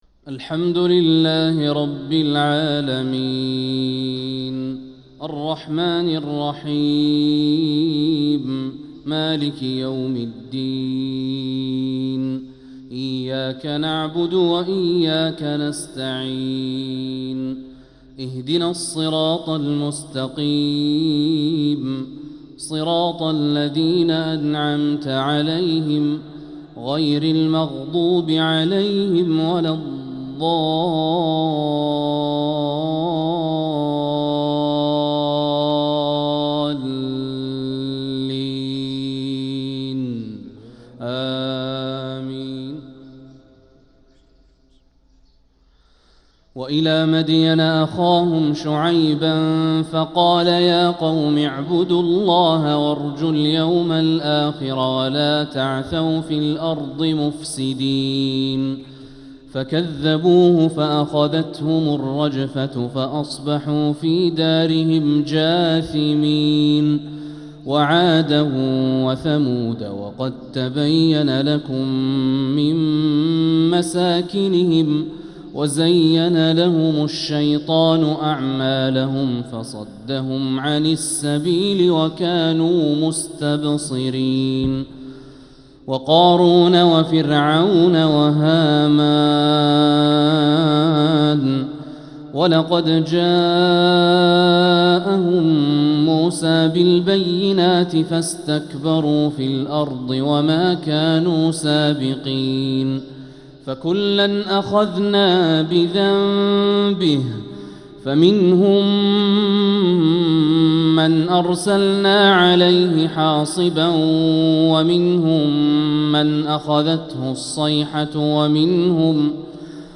فجر السبت 2-8-1446هـ من سورة العنكبوت 36-46 | Fajr prayer from Surat al-Ankabut 1-2-2025 > 1446 🕋 > الفروض - تلاوات الحرمين